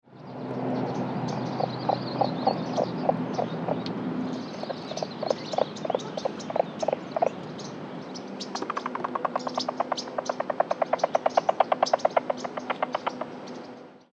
Columbia Spotted Frogs also make other sounds, including a series of grinding grunting release sounds, and a series of rapid knocking sounds which may serve to attract females or repel other males.
Wind, traffic, airplanes, birds, dry reeds, and the occasional land call of a Pacific Treefrog can be heard in the background.
Sound This is a 14 second edited recording of three separate examples of sounds made during an encounter of two male frogs.
rluteiventris409encounters.mp3